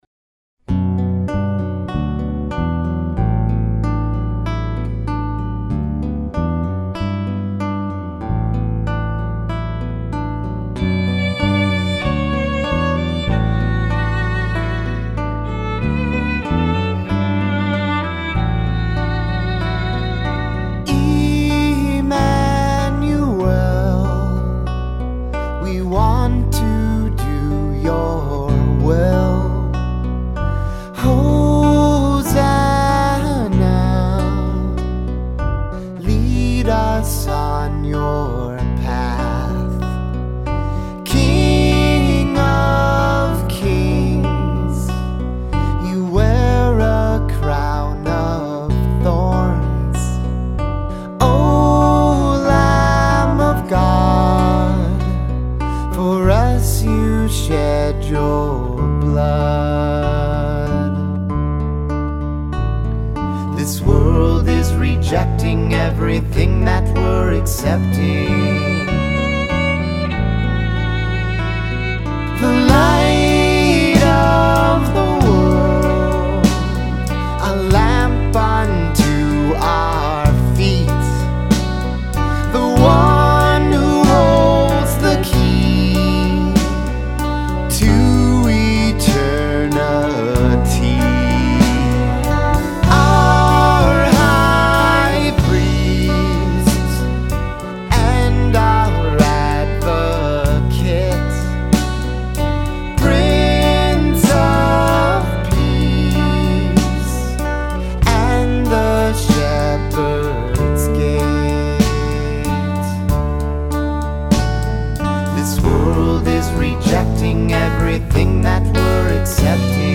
lead vocals, guitar and keyboards
bass
drums
percussion
violin
back vocals